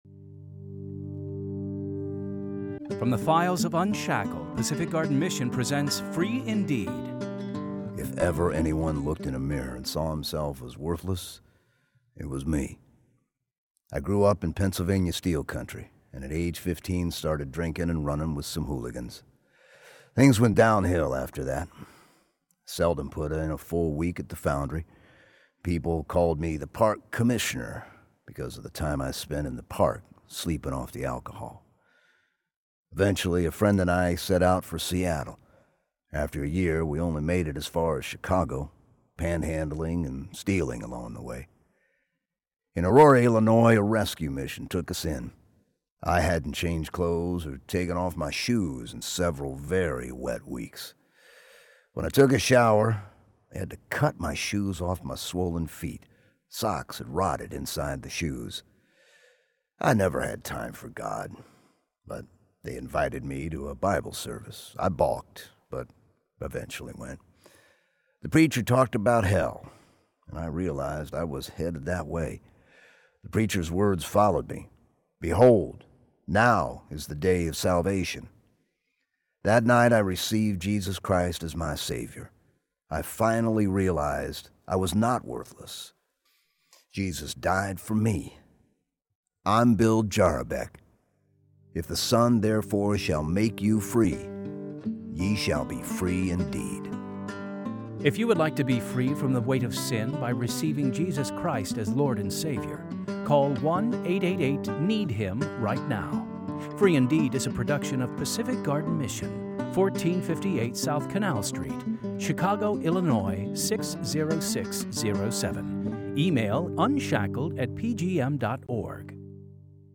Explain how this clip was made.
Free Indeed! stories are created from the files of UNSHACKLED! Radio Dramas, and produced by Pacific Garden Mission.